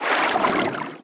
Sfx_creature_titanholefish_swim_01.ogg